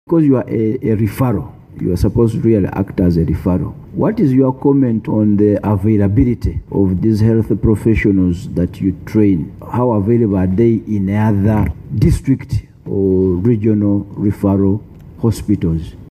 Hon. Asuman Basalirwa (JEEMA, Bugiri Municipality) commended the training institution under the hospital, and asked whether there is a transition policy for the trainees into the hospital system.